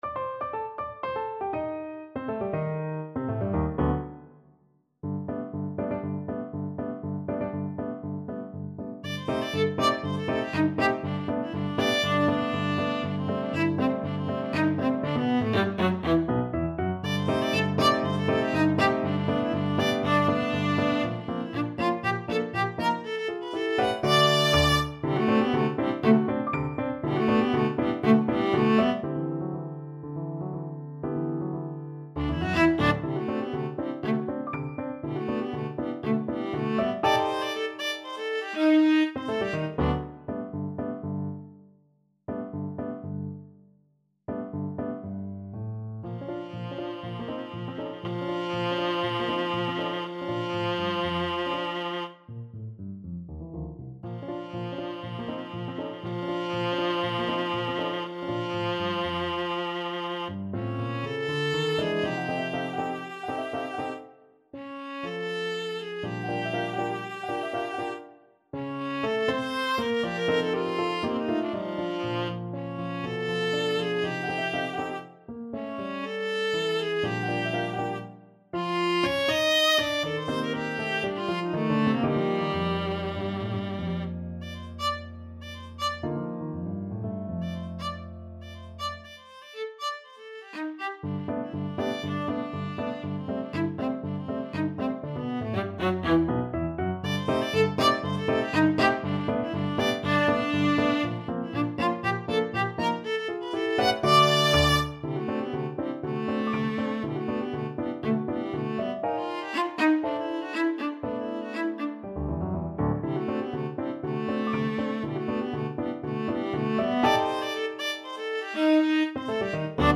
2/4 (View more 2/4 Music)
Allegro giusto (View more music marked Allegro)
Classical (View more Classical Viola Music)